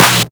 VEC3 Claps 018.wav